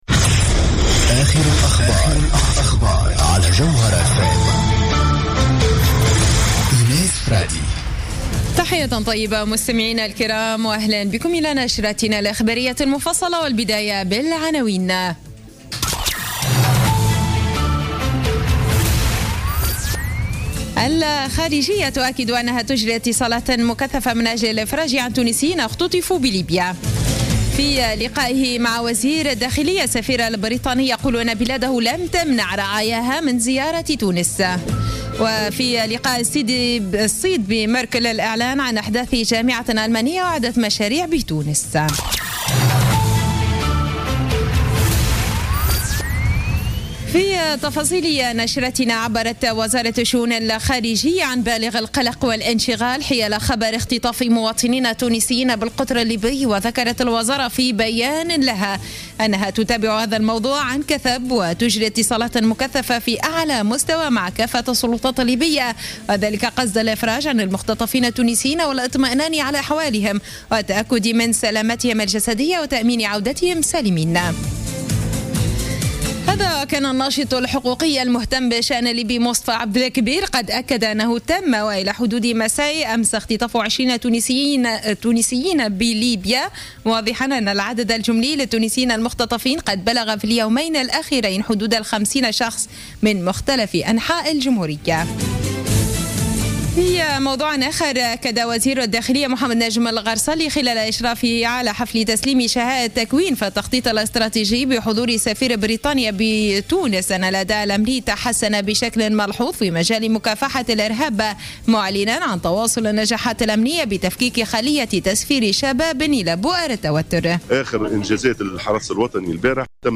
نشرة أخبار السابعة مساء ليوم الخميس 5 نوفمبر 2015